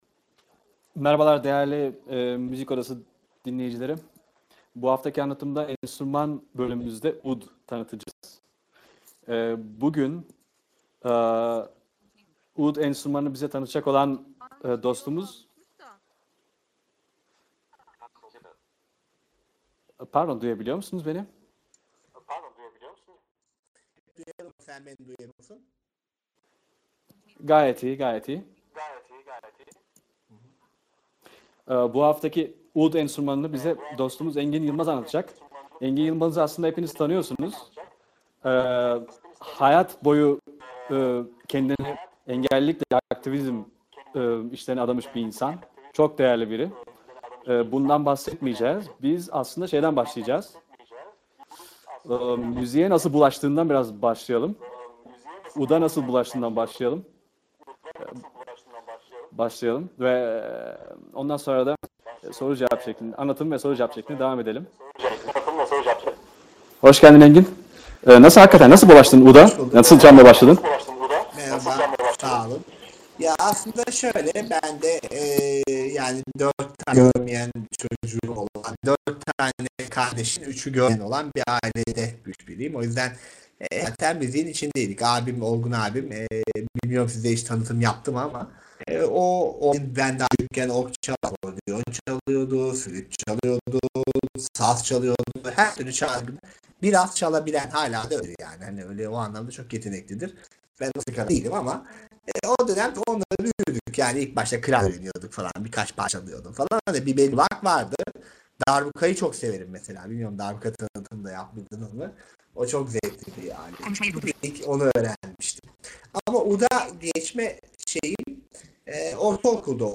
Müzik Odası: Ud | Dünyaya Seslen